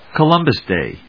アクセントColúmbus Dày 発音を聞く